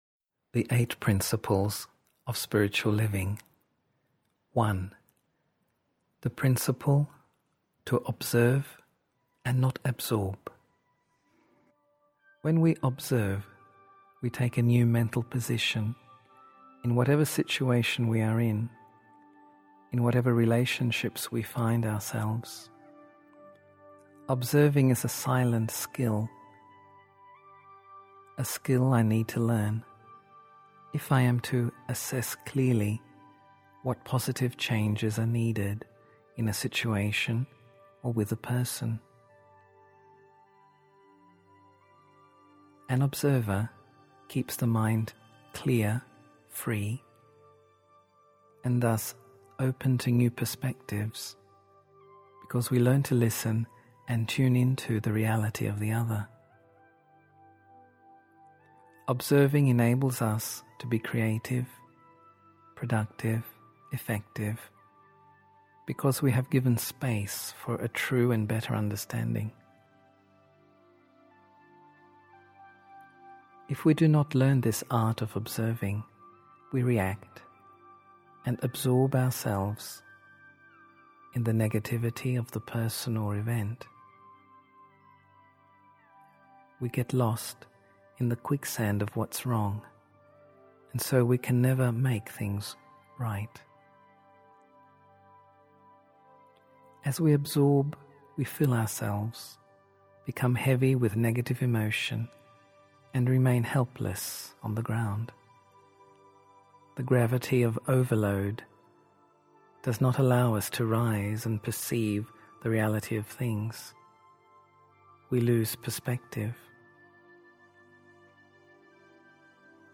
8 Principles of Spiritual Living (EN) audiokniha
Ukázka z knihy